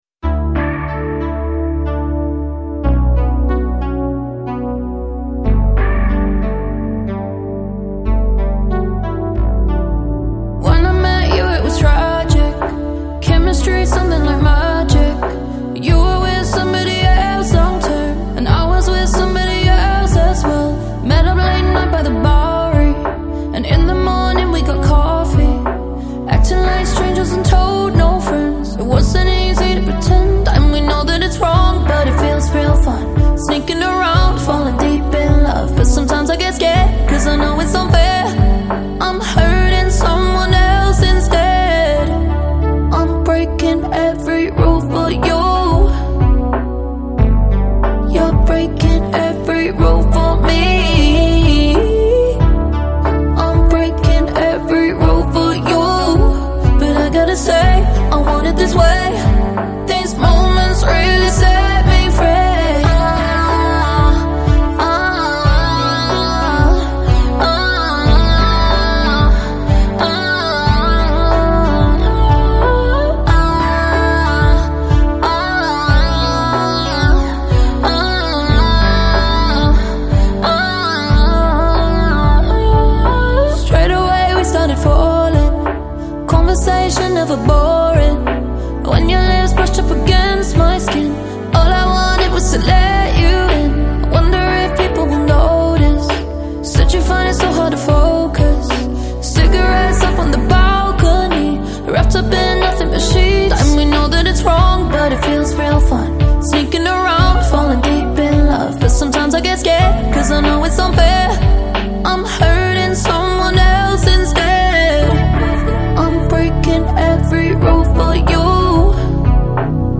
Жанр: Electropop